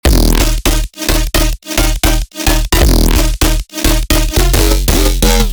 drum & bass samples
Bass Full 26 D#min
Bass-Full-26-Dmin.mp3